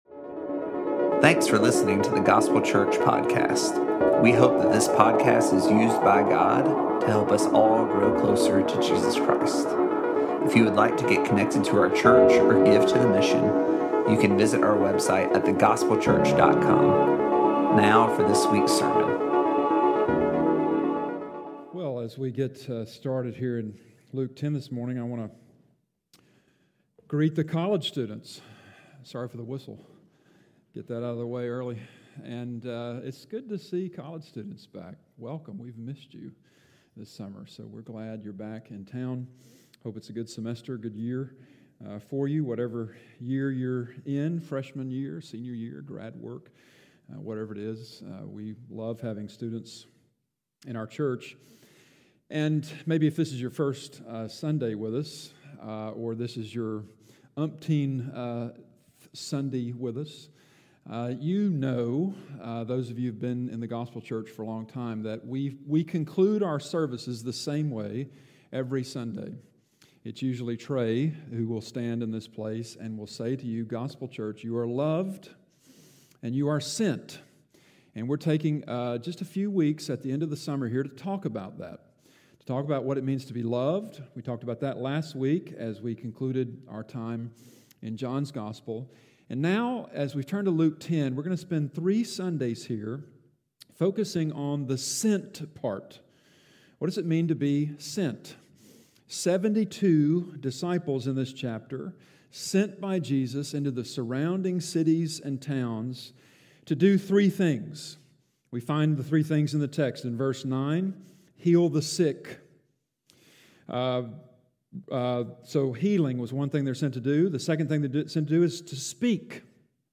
In this sermon from Luke 10:1–24